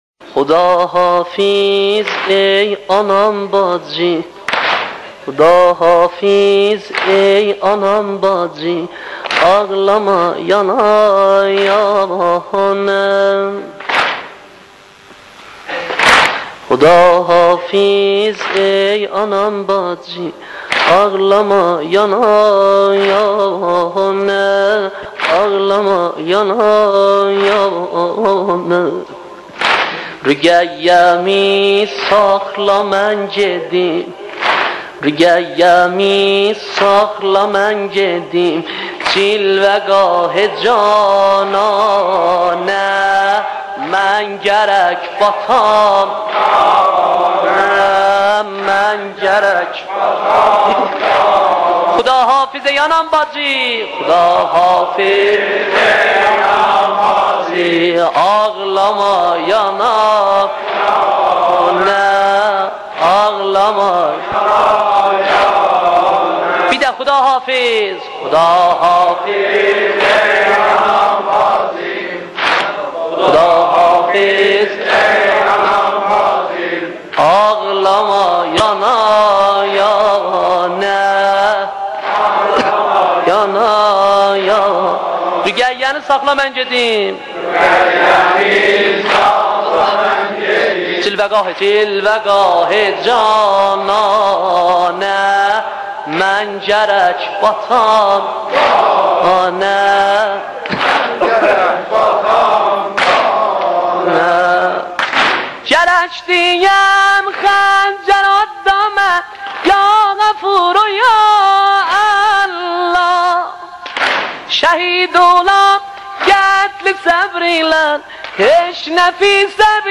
نوحه ترکی